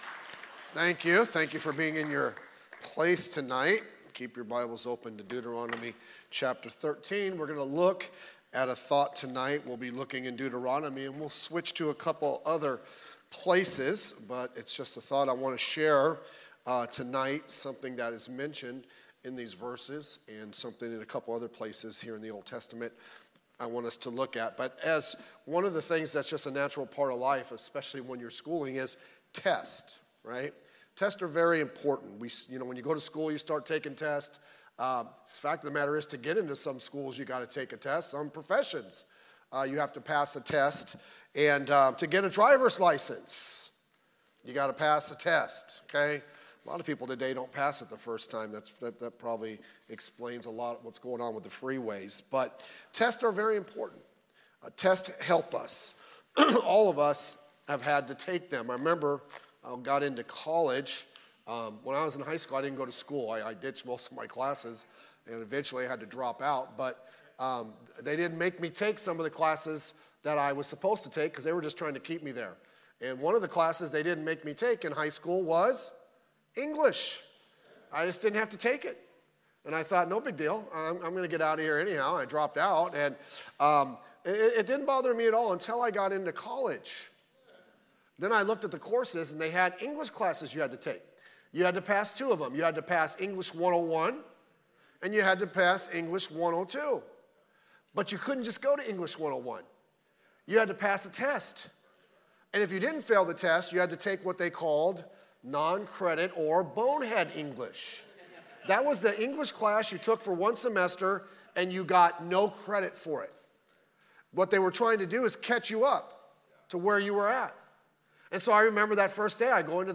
Practical Preaching